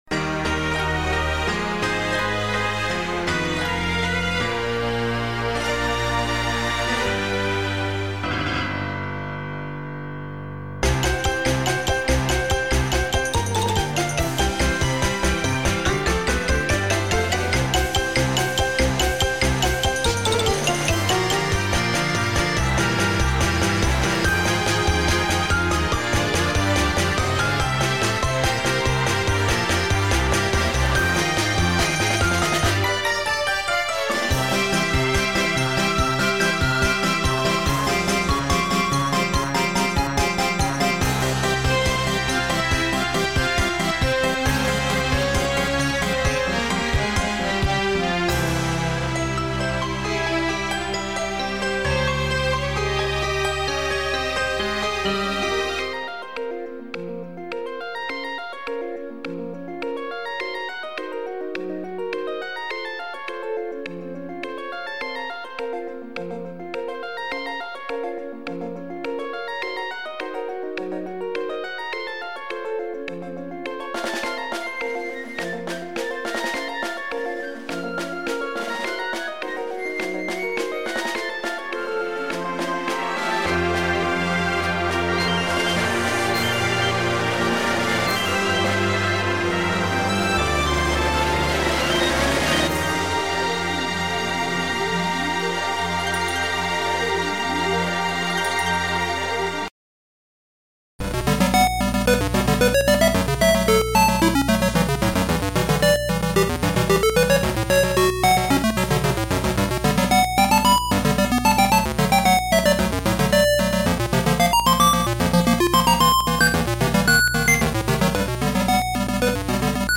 My first video game mix for the site.
and a couple of spooky tracks for Halloween.